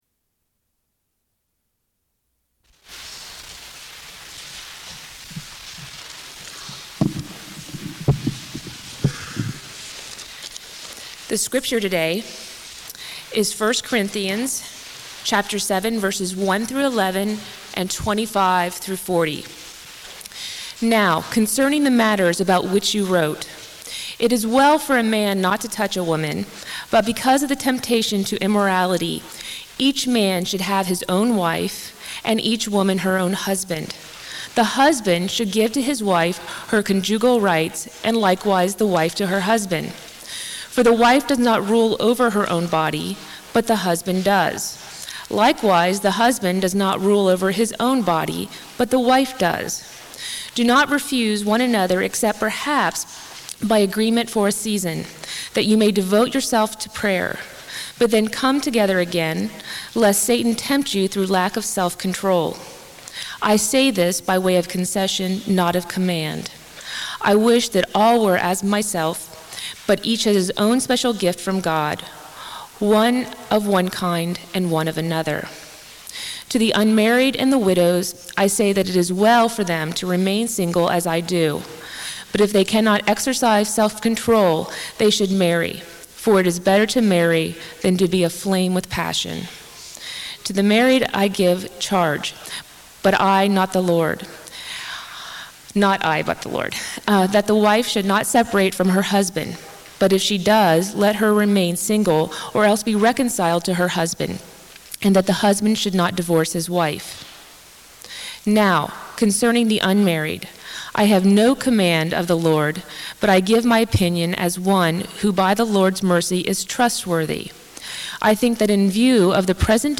I tried to bring the balance of this needed message, delivered twenty one years ago, to a group largely single.
As well, the sermon contains an intriguing comparison between the ancient city of Corinth and current New York City.